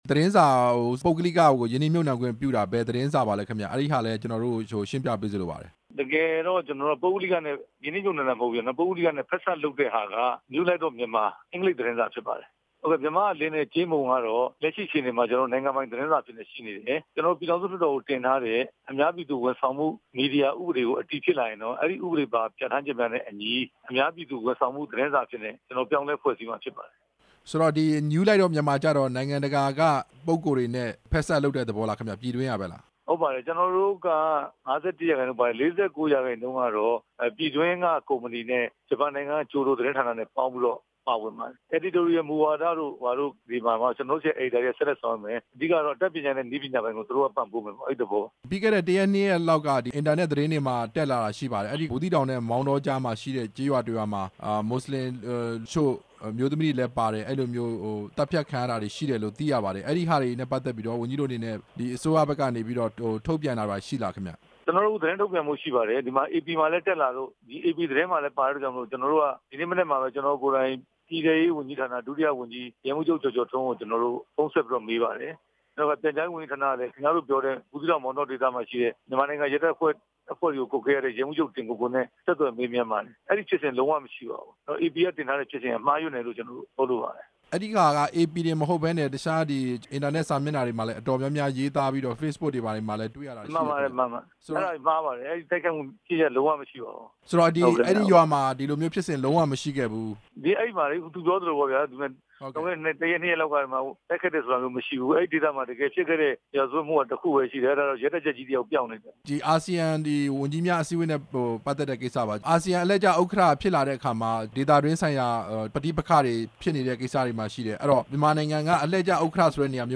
ပြန်ကြားရေး ဒုဝန်ကြီး ဦးရဲထွဋ်နဲ့ မေးမြန်းချက်